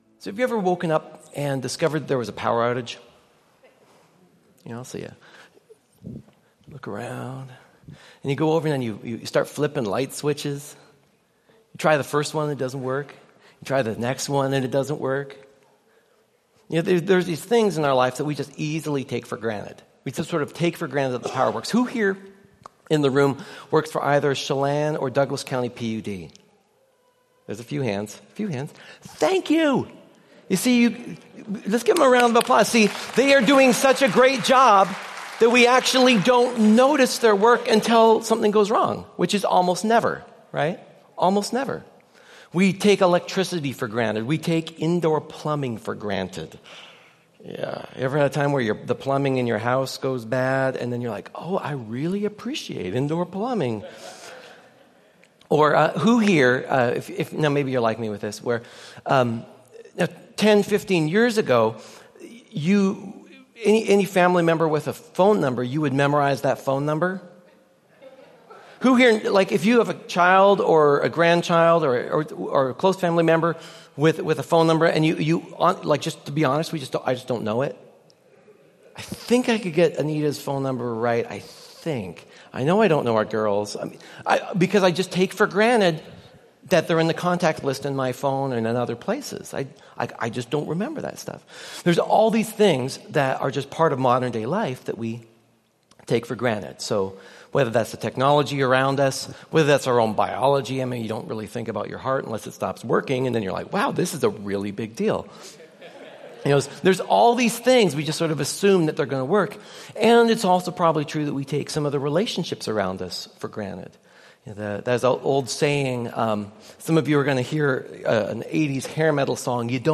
Sermons from Columbia Grove Covenant Church in East Wenatchee WA.